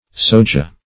soja - definition of soja - synonyms, pronunciation, spelling from Free Dictionary
Soja \So"ja\ (s[=o]"j[.a] or s[=o]"y[.a]), n. (Bot.)